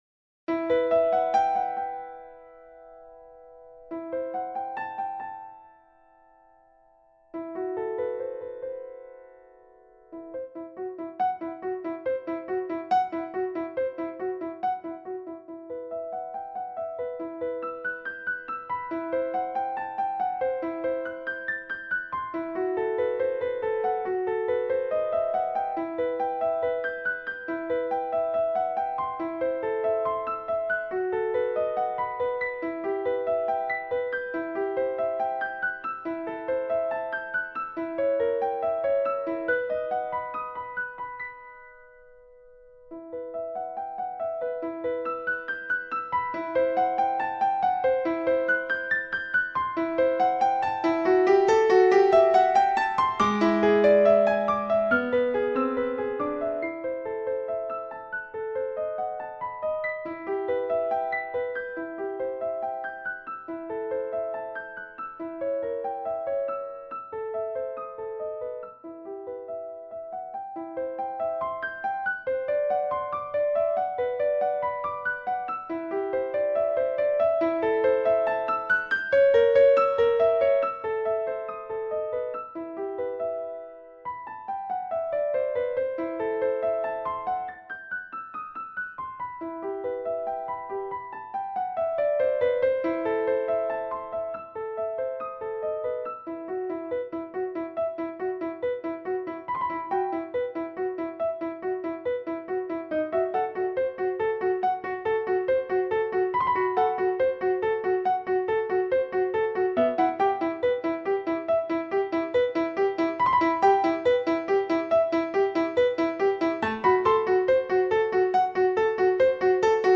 • Теги: Ноты для фортепиано
*.mid - МИДИ-файл для прослушивания нот.